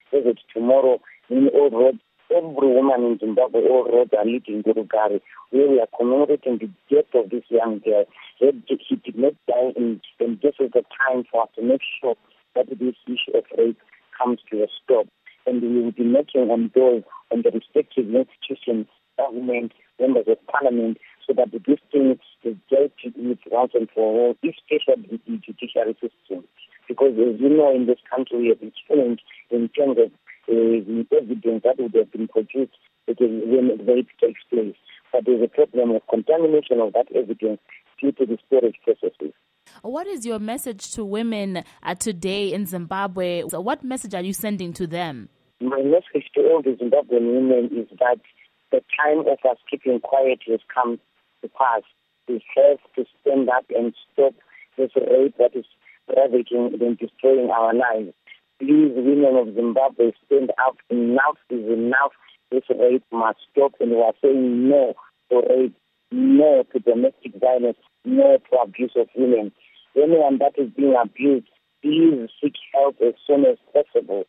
Interview With Tabitha Khumalo